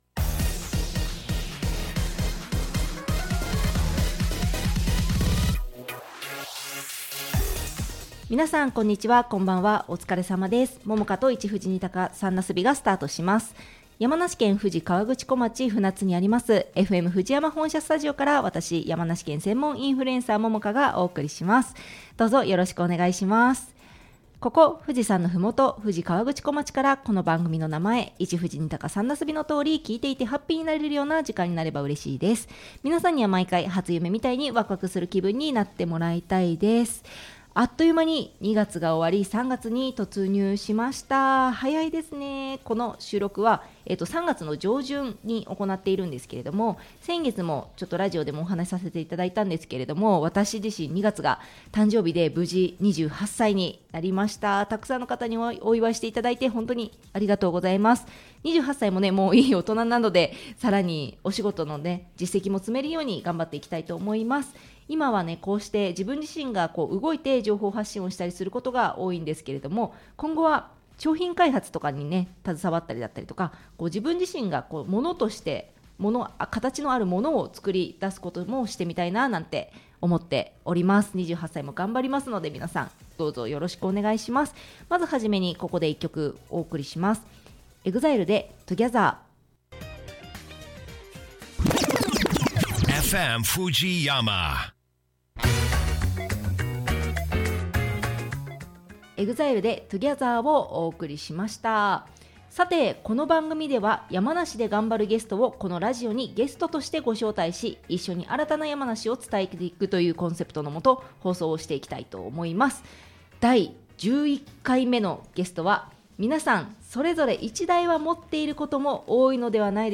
▽放送アーカイブはこちら（ネット配信の為楽曲はカットしています） https